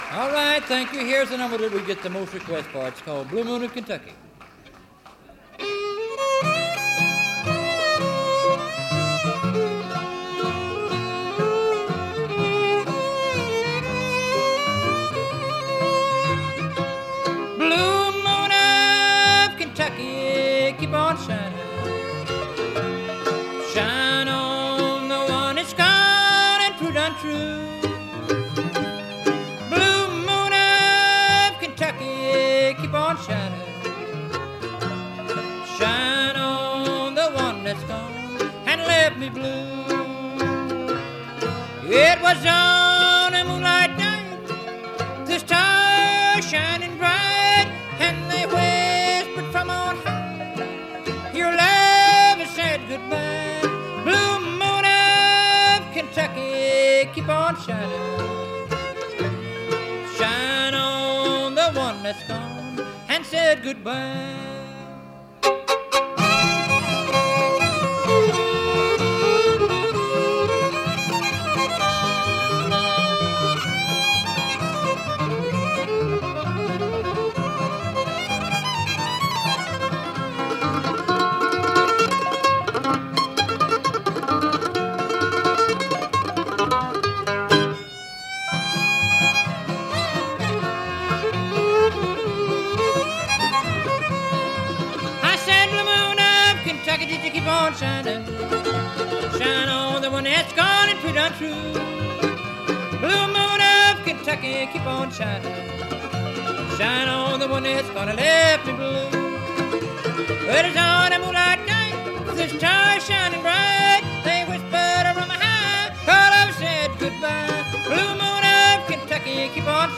Note: Banjo is off-mic the whole show, unfortunately.
Band intros